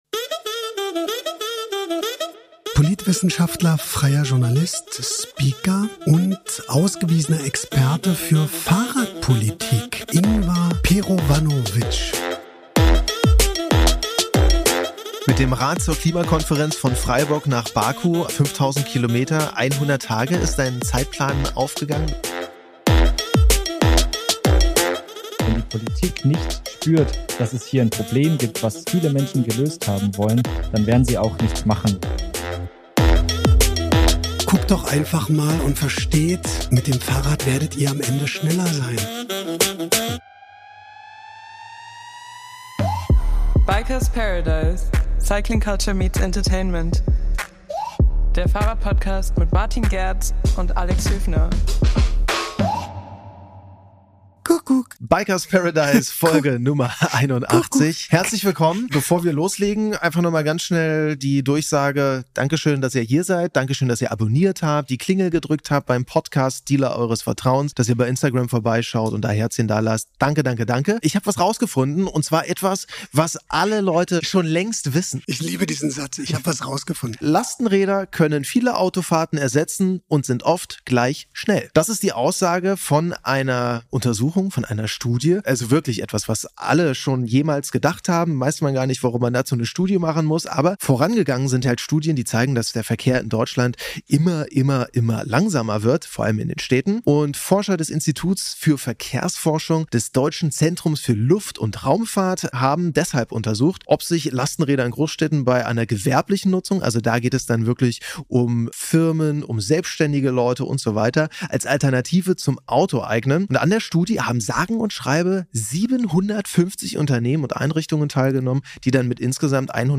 Interview | #81 ~ Bikers Paradise Podcast